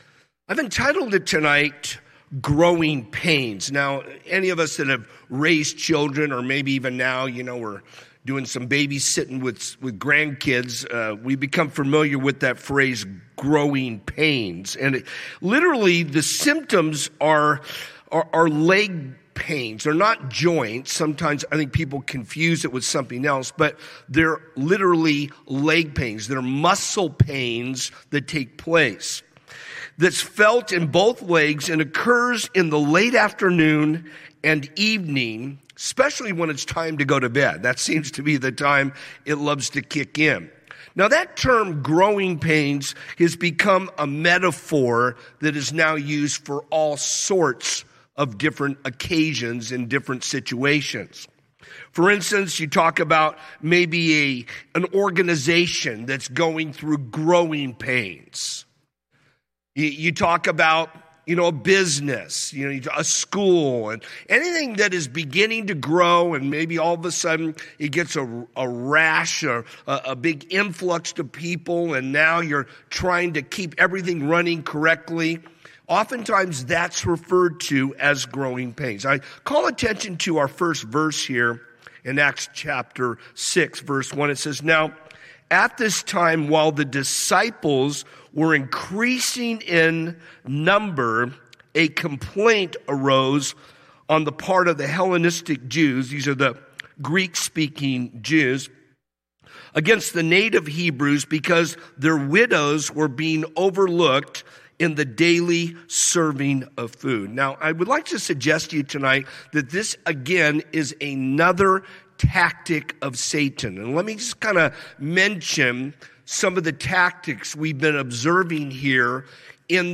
A message from the series "In The Beginning…God."